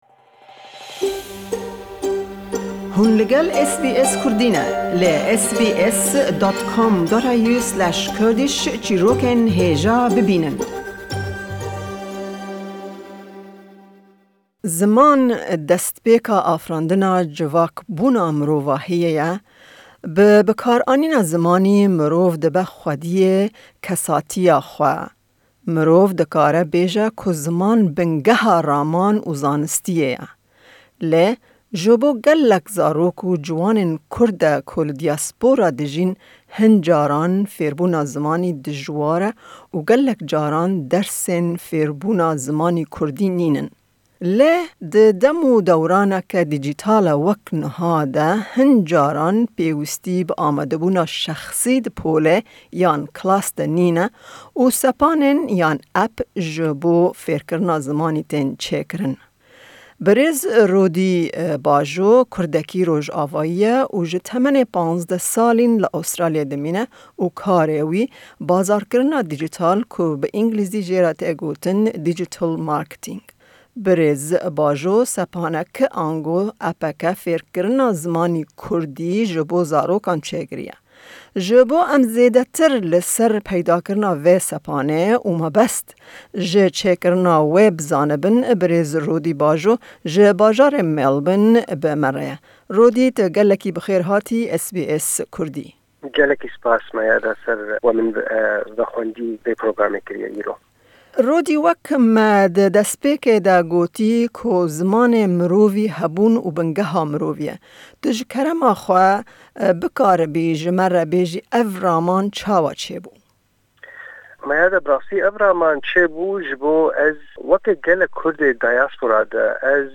em di vê hevpeyvînê de li ser mebest ji çêkirina vê sepanê